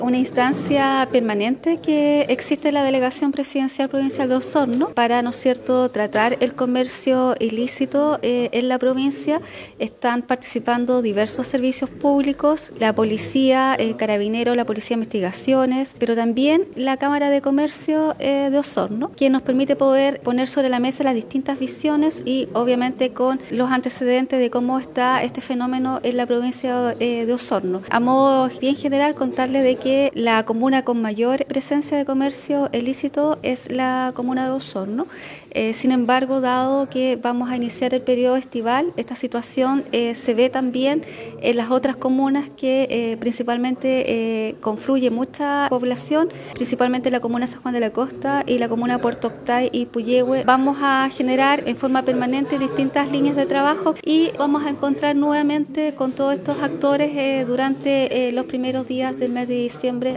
La Delegada Presidencial Provincial, Claudia Pailalef indicó que esta es una instancia permanente para tratar esta problemática que se concentra en la comuna de Osorno, por lo que se irán tomando lineamientos de trabajo para abordar la temporada navideña y el período estival.